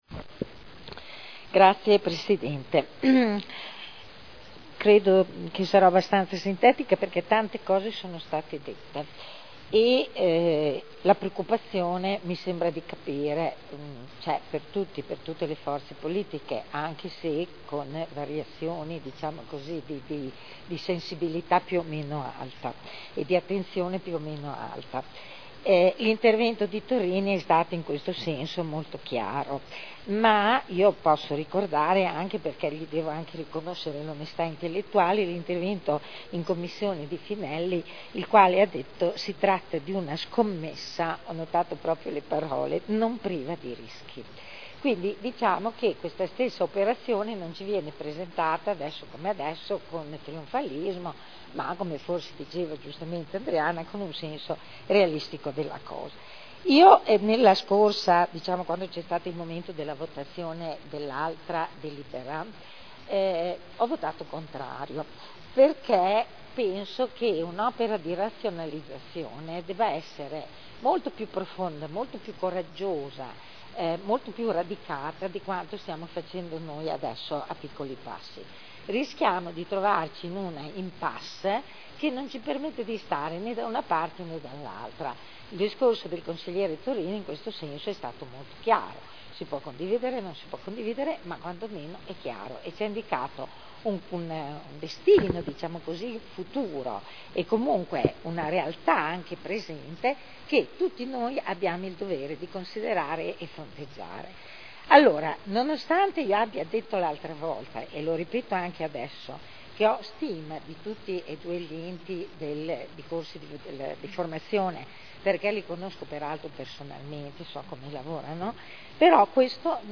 Seduta del 16 aprile. Proposta di deliberazione: Unificazione delle società pubbliche di formazione professionale dell’area modenese. Dibattito